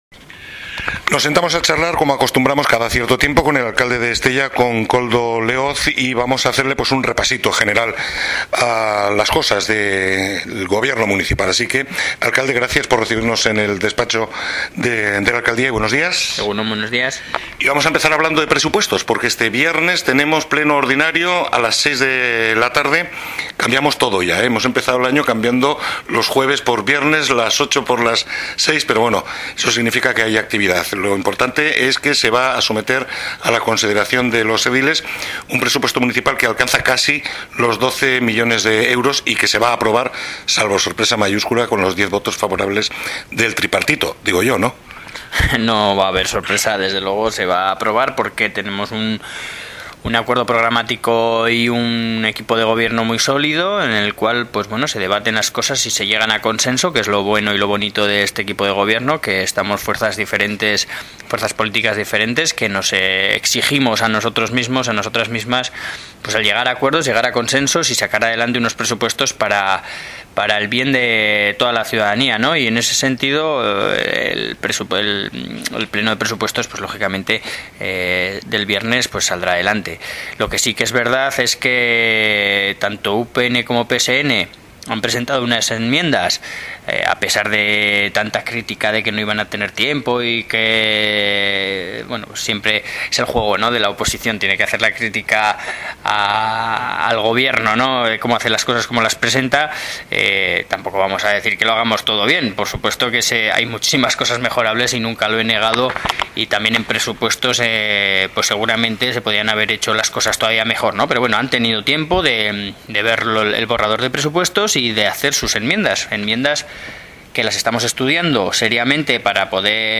Entrevista con un alcalde. Koldo Leoz responde a las preguntas
Tal y como acostumbramos con una frecuencia indeterminada, al final la que manda la propia actualidad, nos sentamos en el despacho de la alcaldía para hablar de los asuntos de Estella.